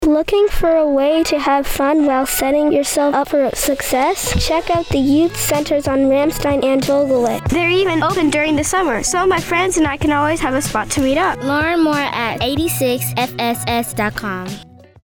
Radio Spot - Youth Center
This 15-second spot publicizes the Youth Centers on Vogelweh Air Base and Ramstein Air Base, Germany.